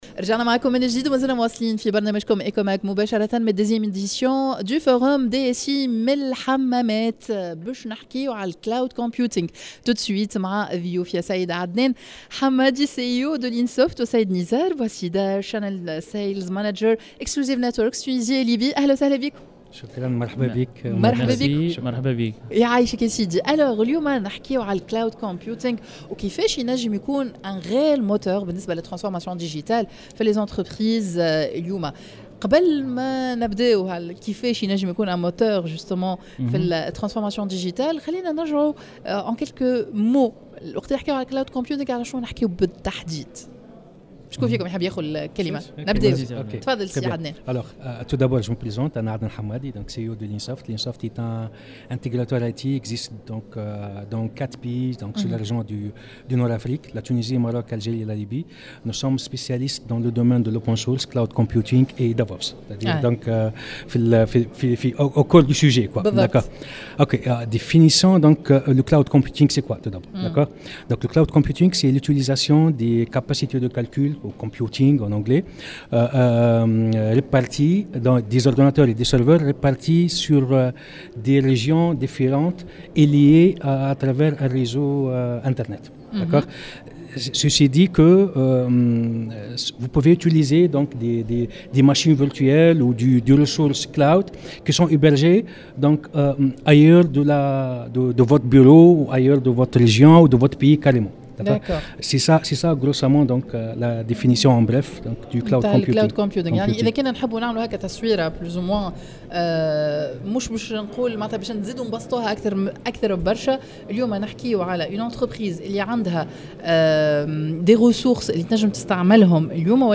dans un plateau spécial en direct de Yasmine El Hammamet